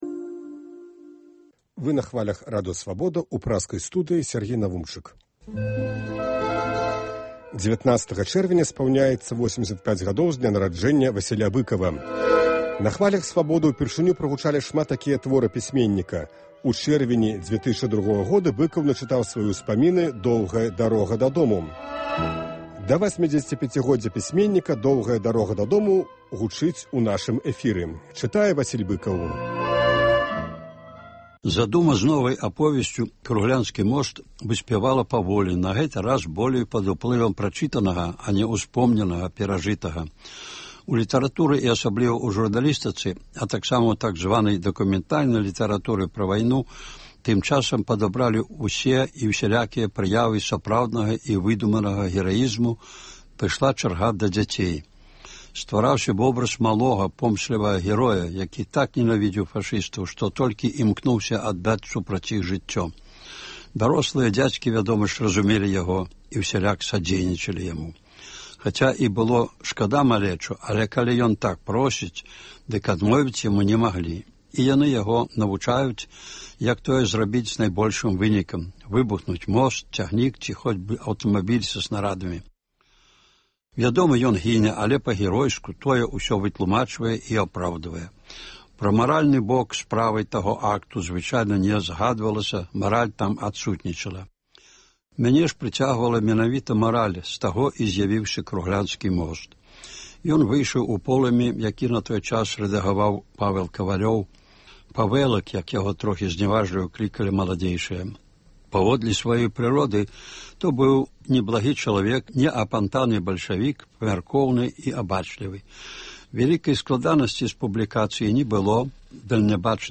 19 чэрвеня спаўняецца 85 гадоў з дня нараджэньня Васіля Быкава. Сёлета ў чэрвені штодня ў нашым эфіры гучыць “Доўгая дарога дадому” ў аўтарскім чытаньні.